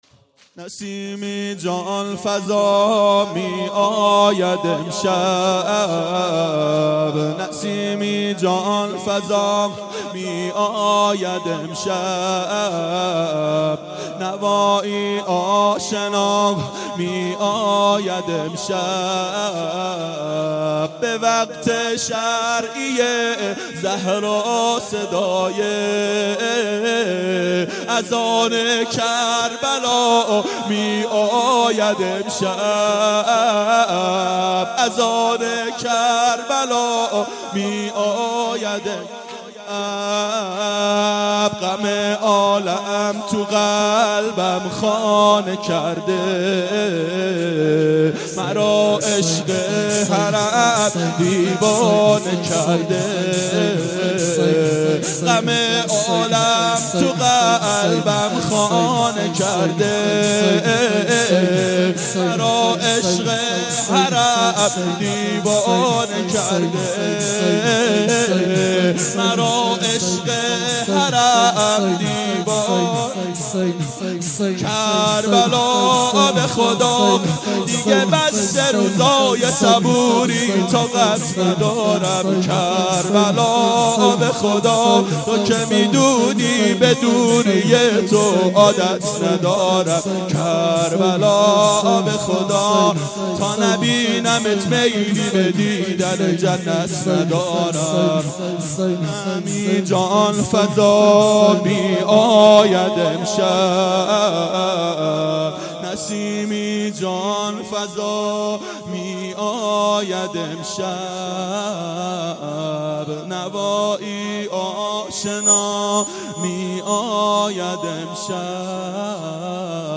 شب اول محرم 92 هیأت عاشقان اباالفضل علیه السلام منارجنبان